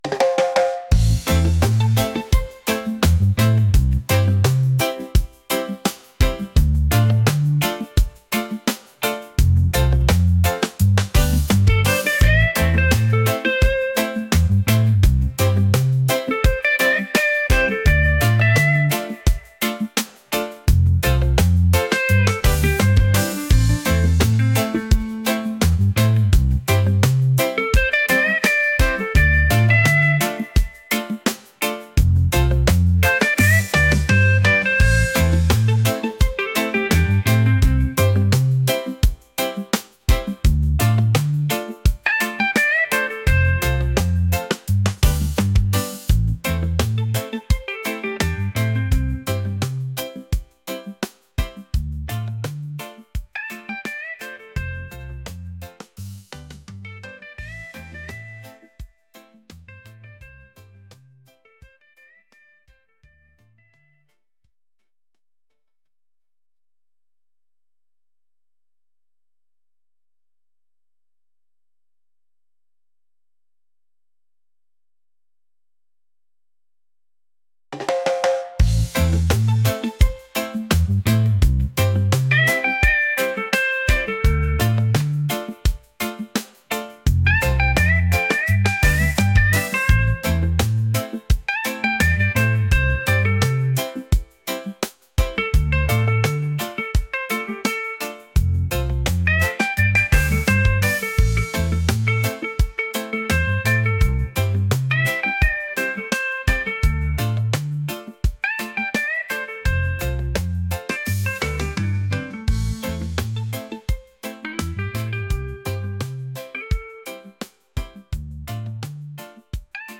soulful | reggae | upbeat